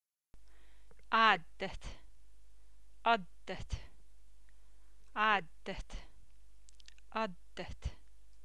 addet.mp3